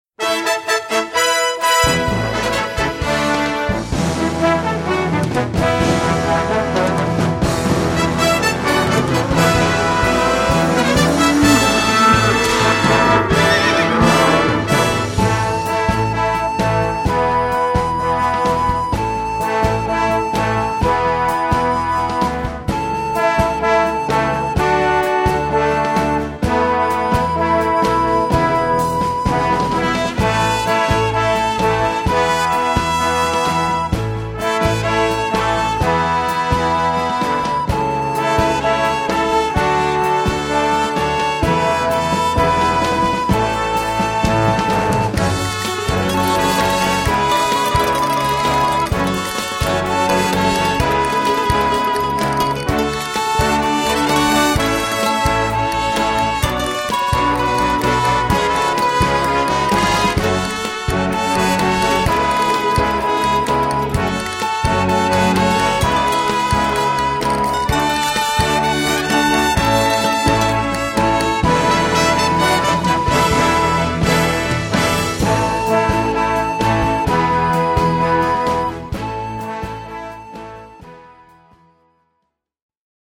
Voicing: Recorder Collection